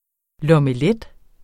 Udtale [ lʌməˈlεd ]